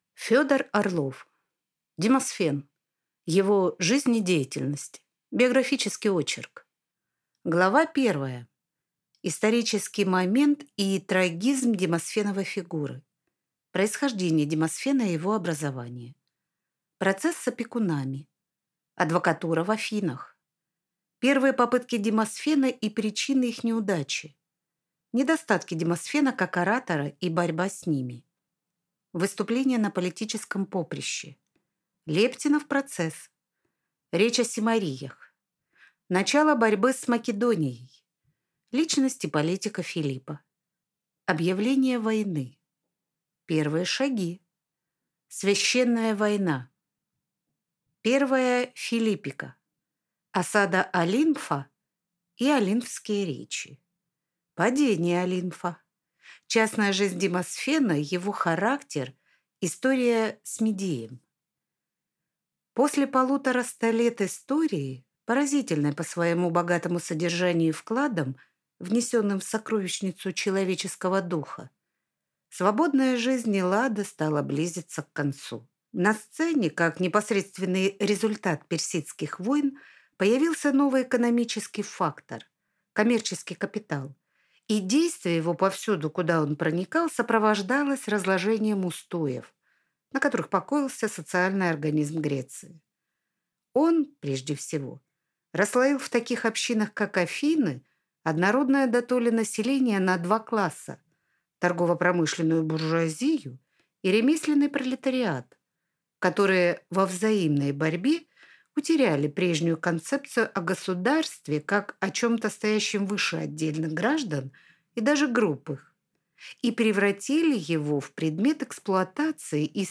Аудиокнига Демосфен. Его жизнь и деятельность | Библиотека аудиокниг